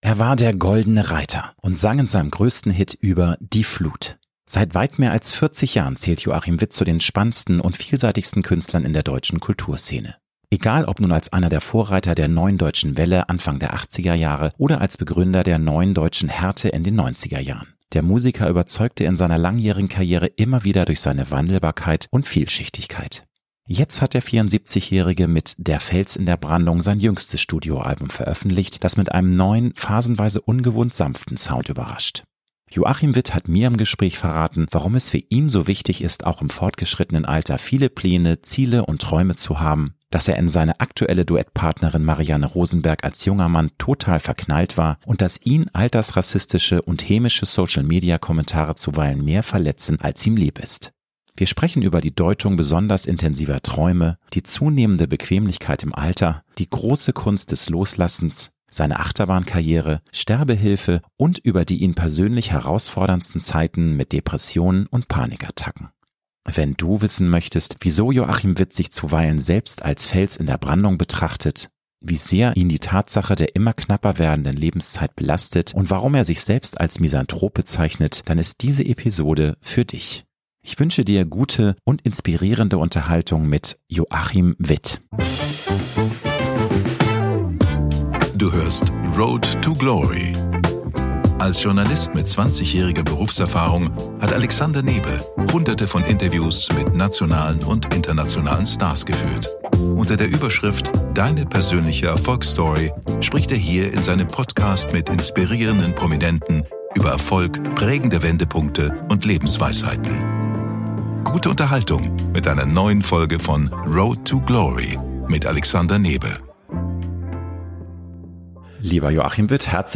Joachim Witt hat mir im Gespräch verraten, warum es für ihn so wichtig ist, auch im fortgeschrittenen Alter viele Pläne, Ziele und Träume zu haben, dass er in seine aktuelle Duettpartnerin Marianne Rosenberg als junger Mann total verknallt war und dass ihn altersrassistische und hämische Social-Media-Kommentare zuweilen mehr verletzen, als ihm lieb ist. Wir sprechen über die Deutung besonders intensiver Träume, die zunehmende Bequemlichkeit im Alter, die große Kunst des Loslassens, seine Achterbahnkarriere, Sterbehilfe und über die ihn persönlich herausforderndsten Zeiten mit Depressionen und Panikattacken.